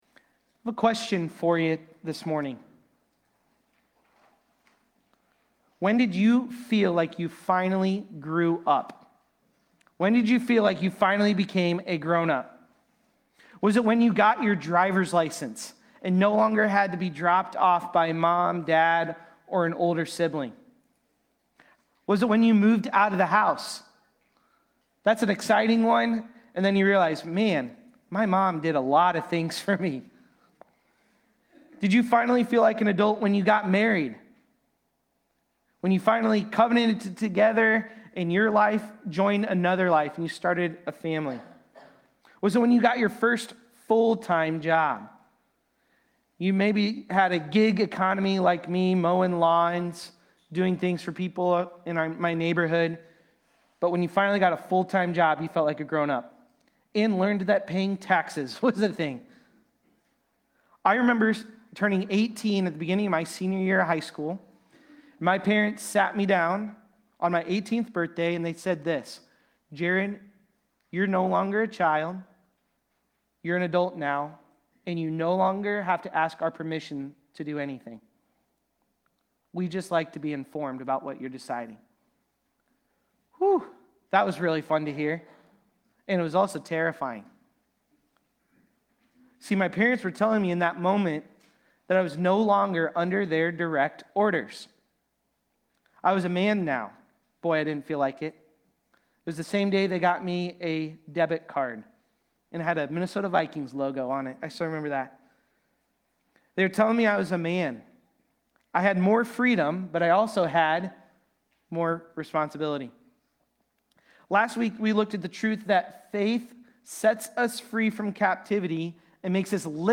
Galatians-4.1-7-Sermon-Audio.mp3